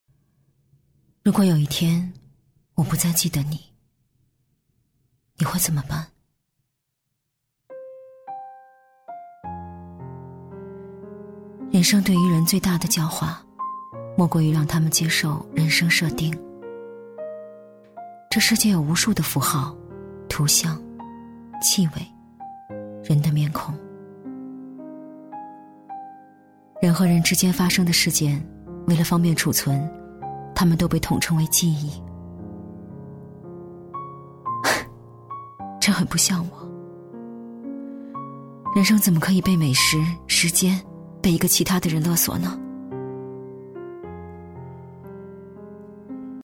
女国语330
【旁白】30岁女性伤感自白